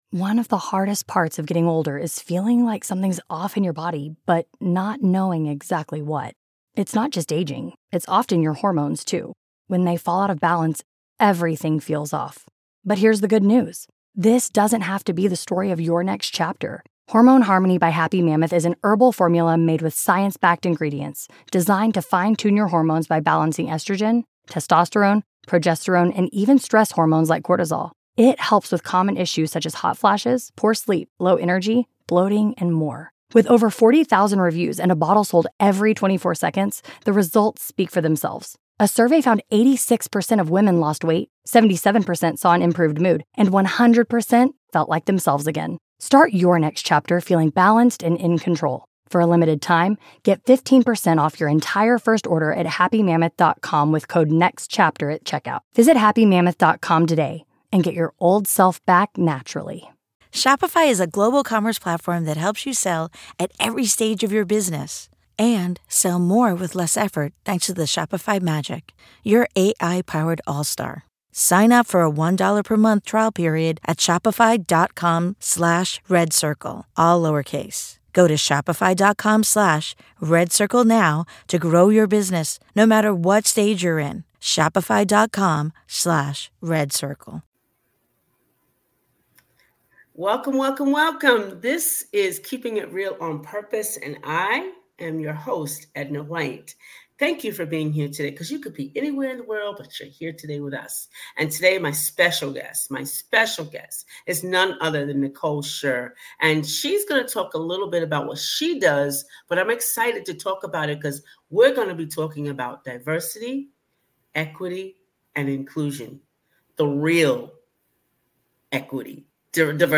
In this insightful conversation, we'll explore: